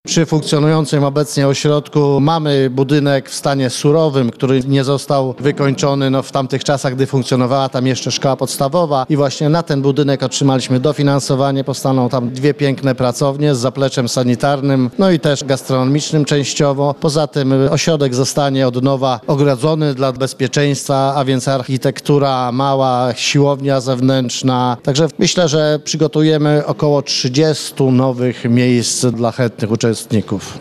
Ośrodek zostanie ogrodzony, będzie infrastruktura mała i siłowania zewnętrzna – mówi wójt gminy Tuczna Zygmunt Litwiniuk.